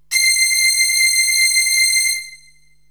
STRINGS 0007.wav